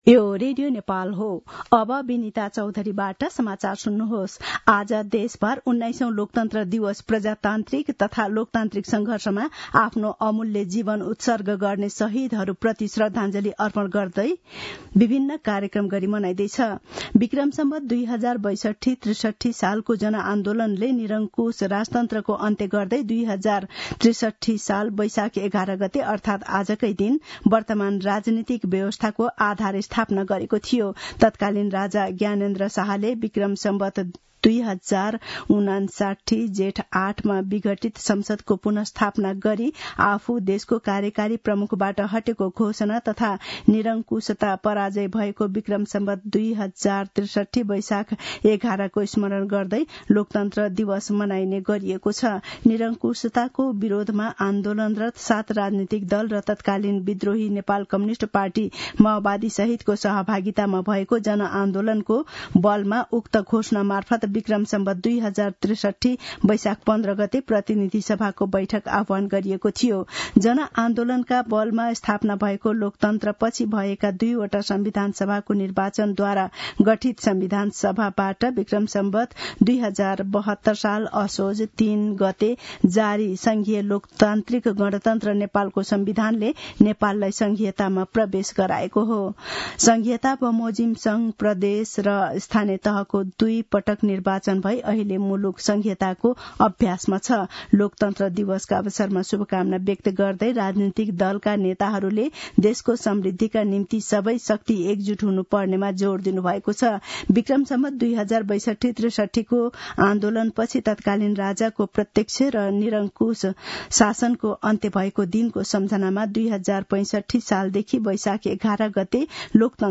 मध्यान्ह १२ बजेको नेपाली समाचार : ११ वैशाख , २०८२